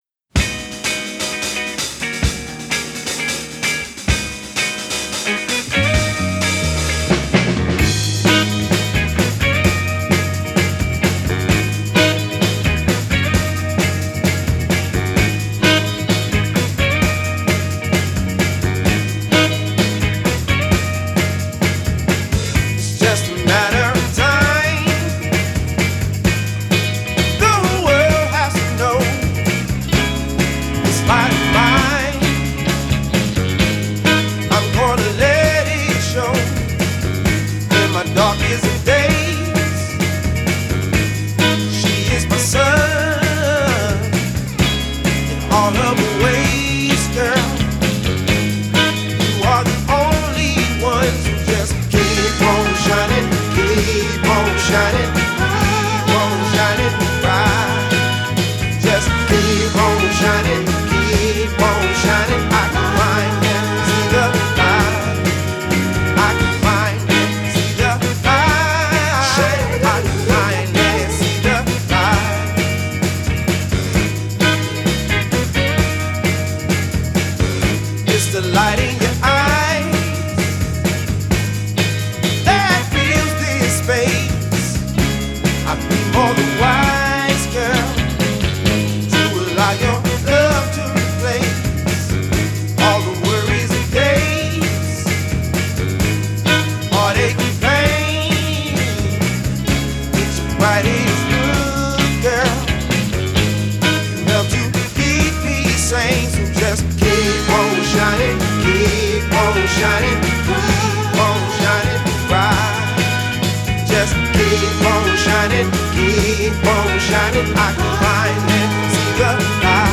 wieder so ein künstler, der sich am modernen soul versucht!
stark vom funk und blues beeinflusst